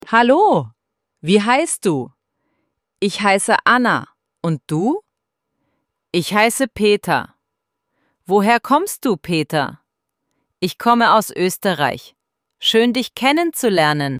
ElevenLabs_Text_to_Speech_audio-38.mp3